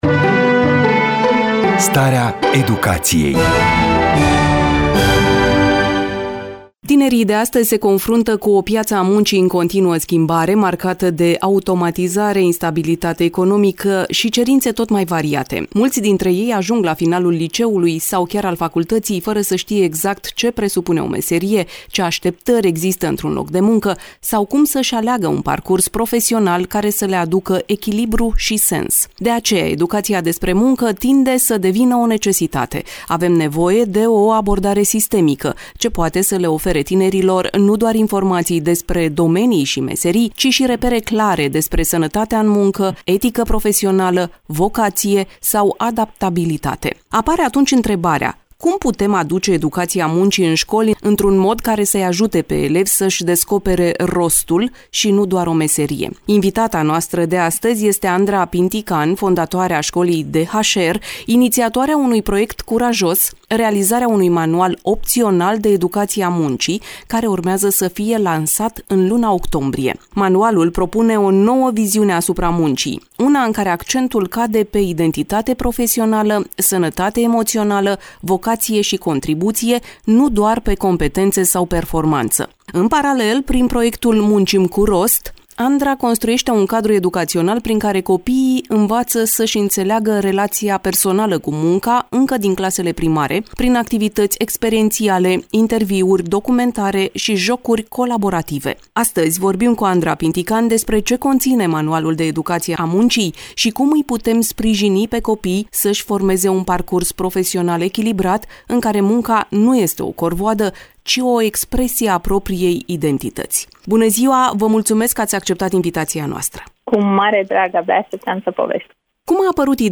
Varianta audio a interviului